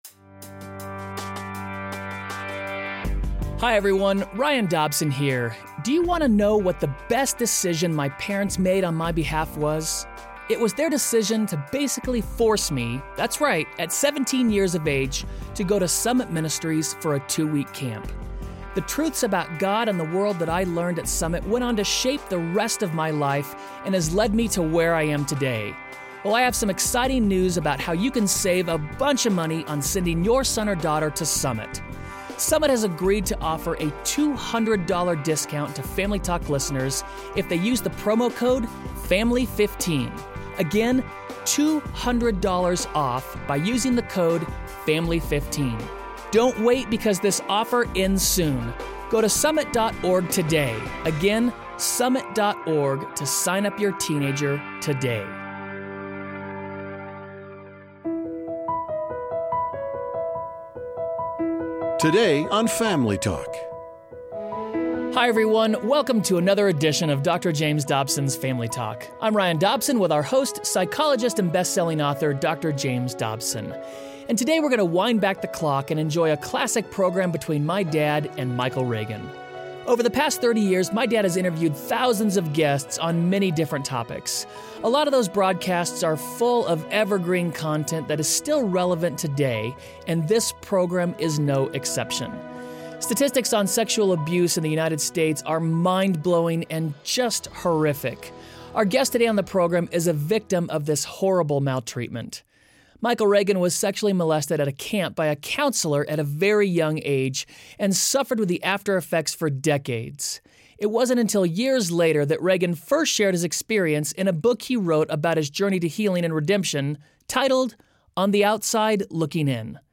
On the next edition of Family Talk Dr. James Dobson interviews Michael Reagan about how God healed him from deep wounds at the hands of a camp counselor, and equipped him to help others.